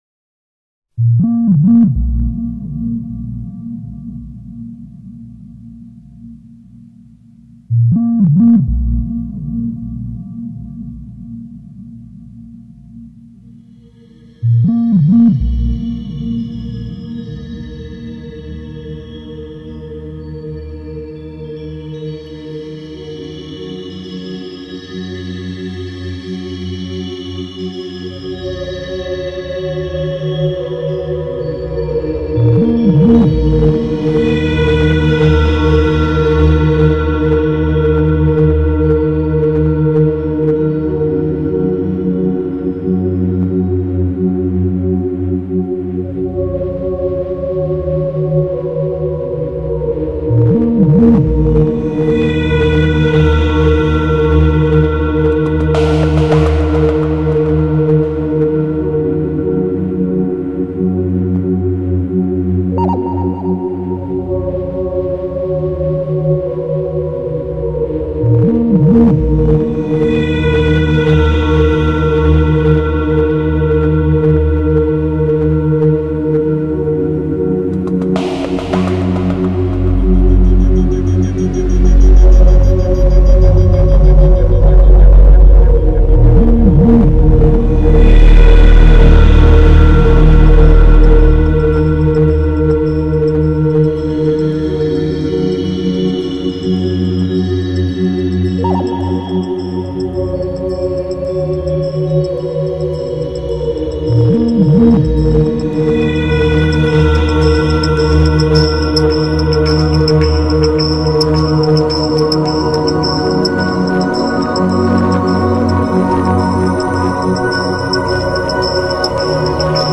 音乐类型：电子音乐
飘渺之乐如宇宙传音,又如梦萦天仙飞舞.随着节奏的变换,把我们仿佛在天边遨游~~~~